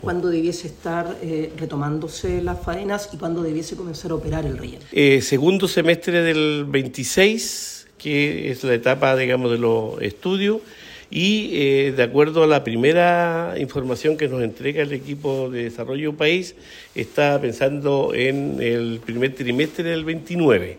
Ante las consultas de Radio Bío Bío, Cuvertino dijo que las obras podrían reanudarse durante el segundo semestre del 2026 con la ejecución de los estudios, estimando que el relleno sanitario podría estar operativo en el primer trimestre del 2029.